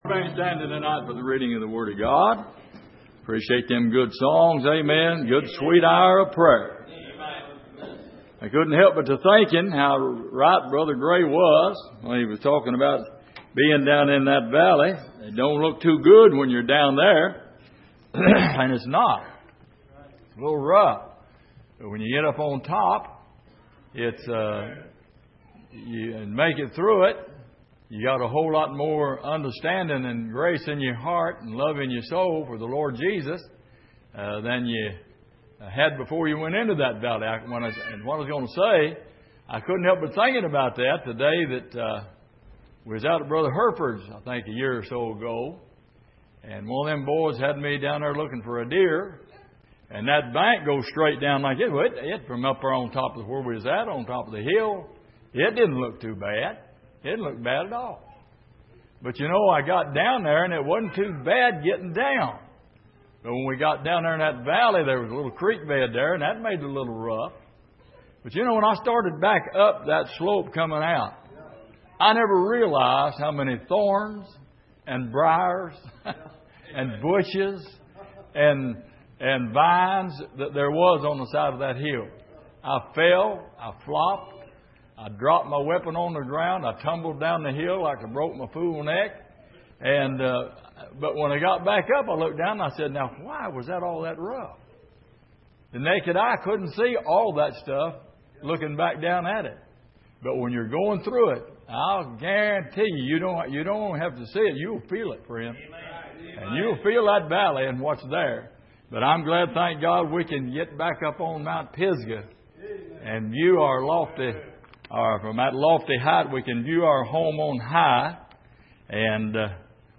Passage: Psalm 26:1-12 Service: Sunday Evening